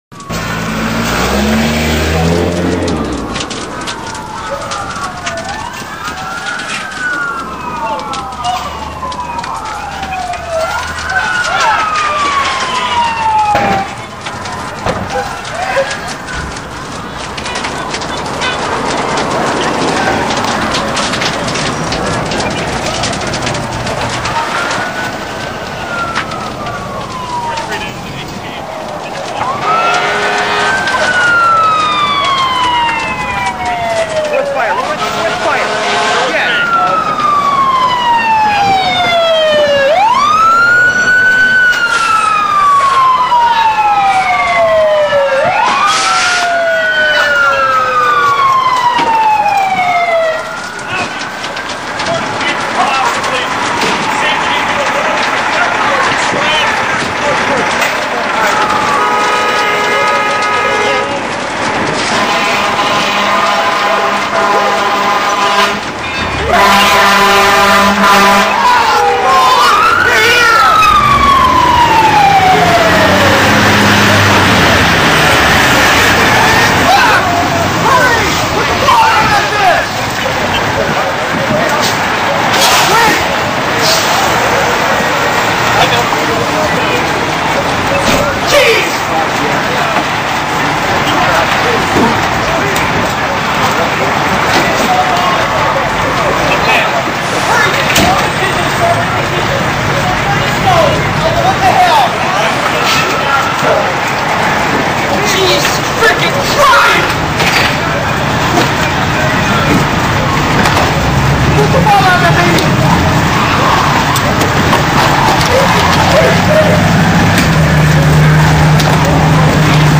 Fireground Sound FX.MP3